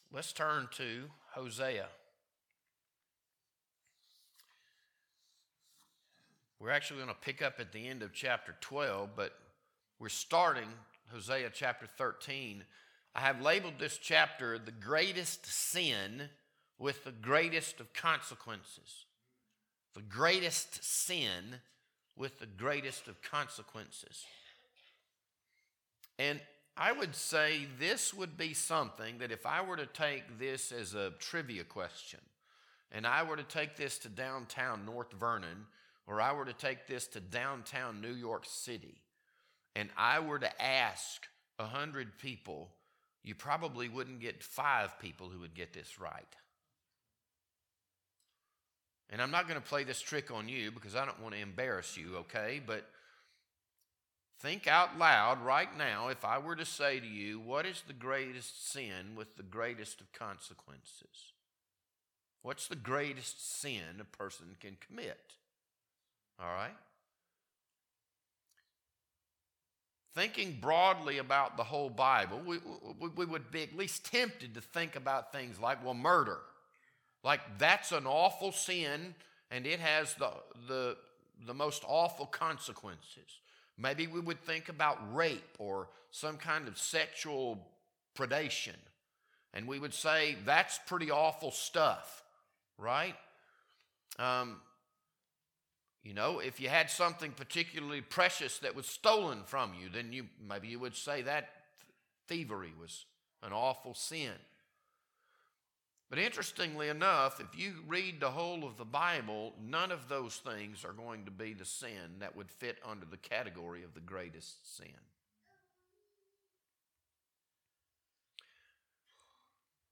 This Sunday evening sermon was recorded on March 1st, 2026.